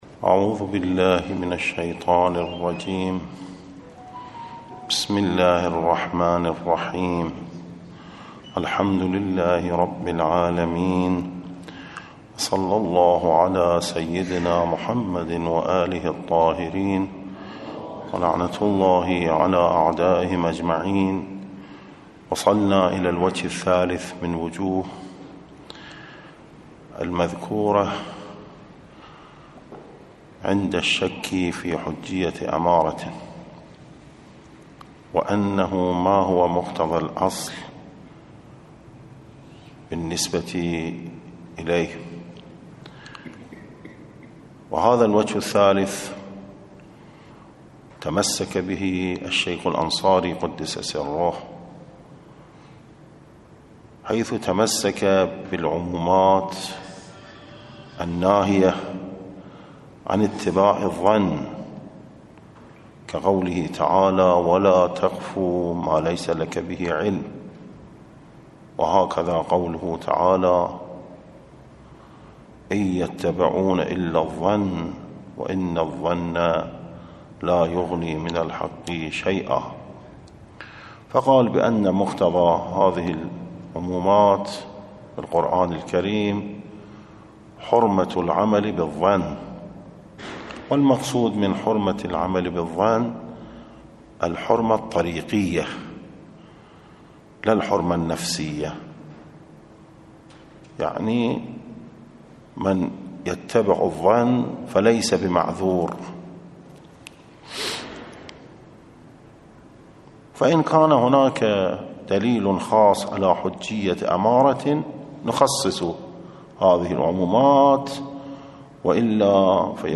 الدرس: 65